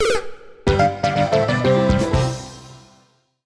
An arrangement